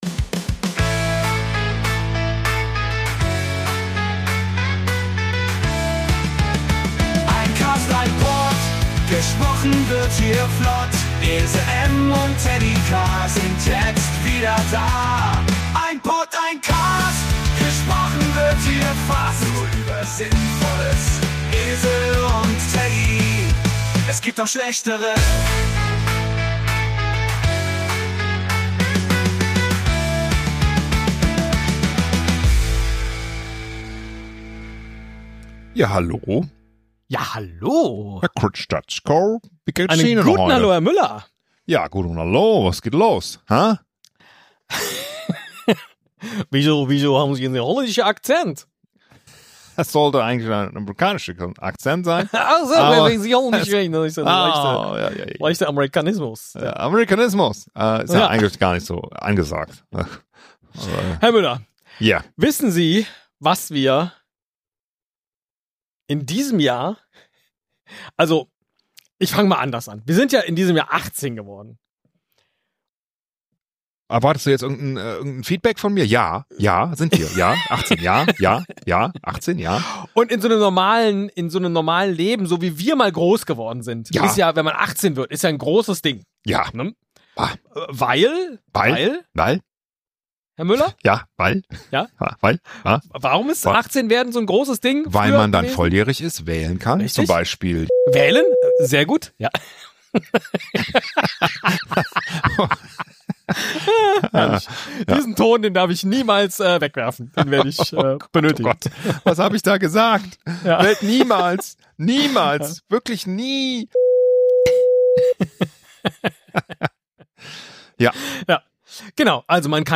Der Motor des Käfers röchelt.